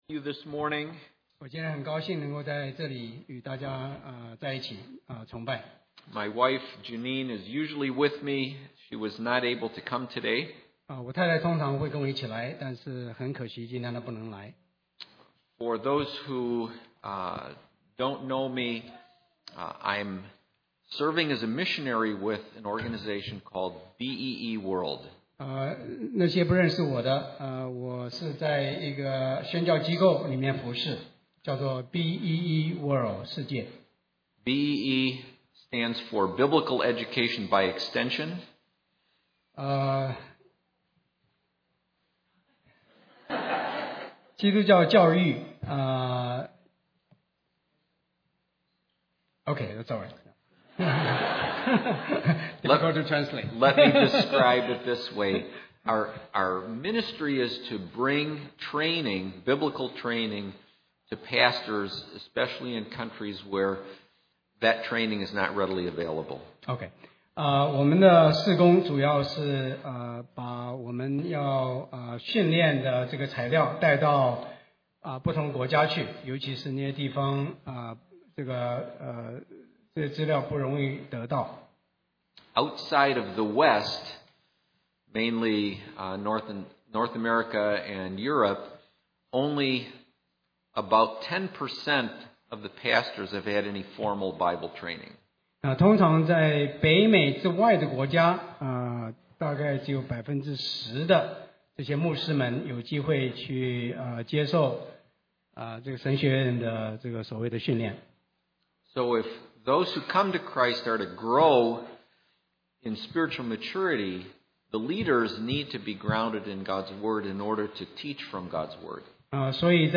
英文講道